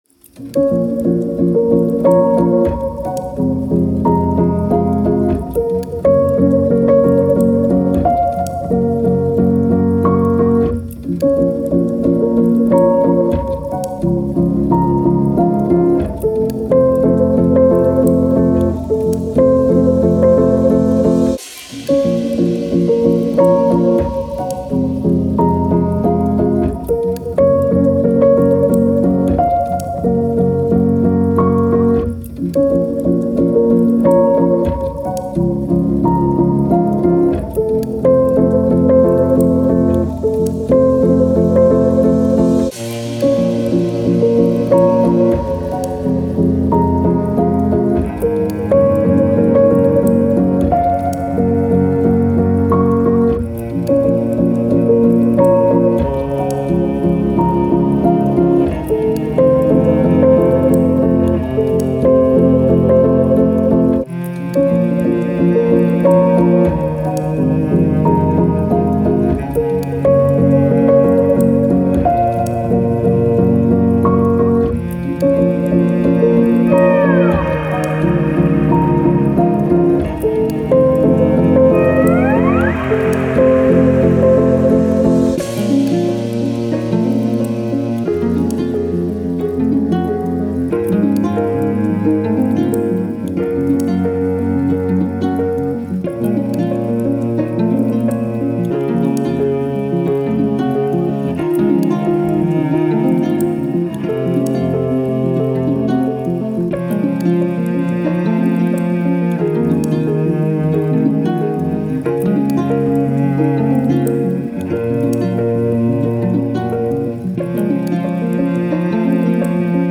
Piano, Soundtrack, Classical, Emotional, Sad